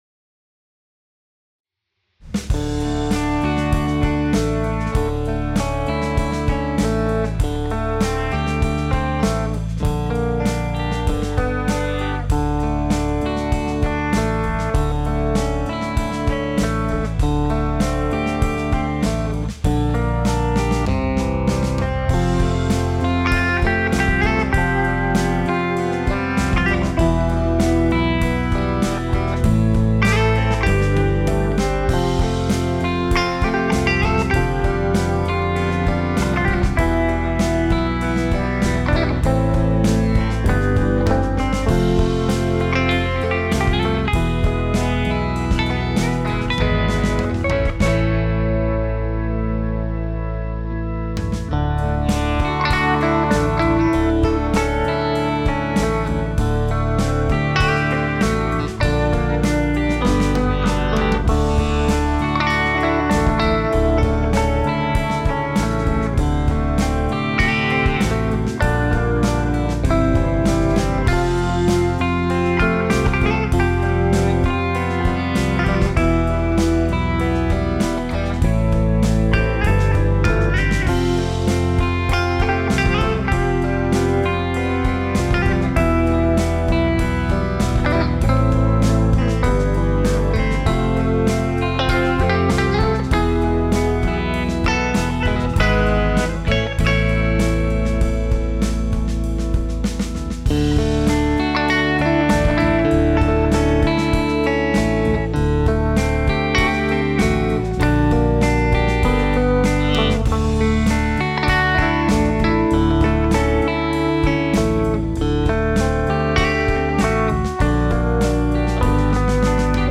Guitars
Keyboard, bass & drums